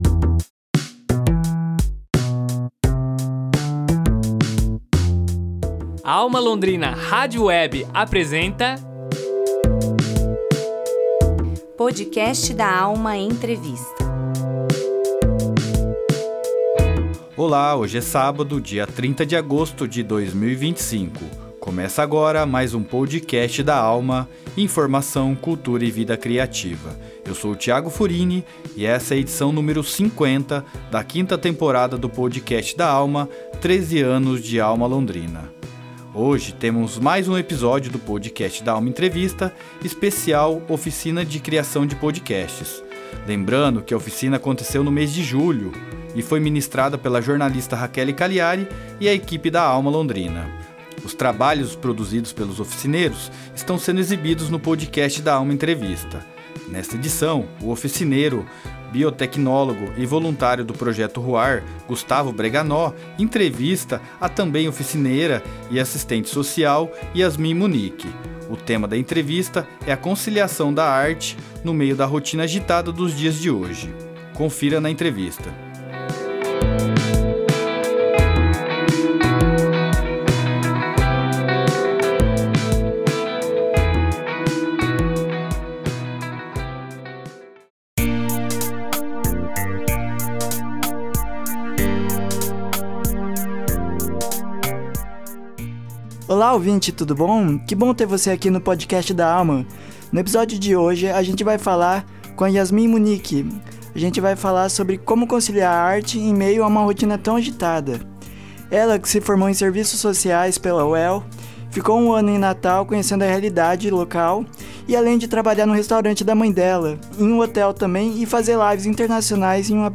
Mais um episódio do Podcast da Alma Entrevista, especial Oficina de Criação de Podcast.